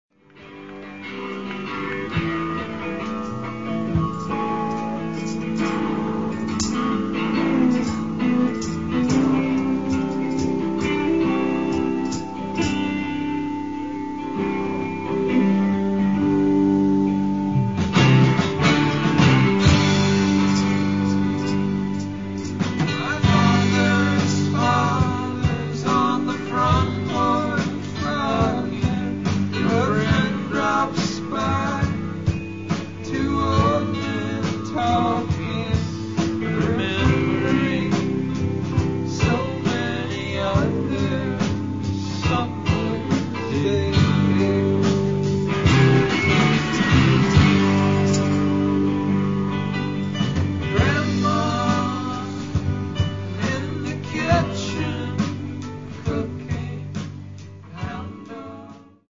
Sessions radios & lives inédits
Nick's, Philadelphia - 1997